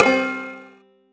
countdown_01.wav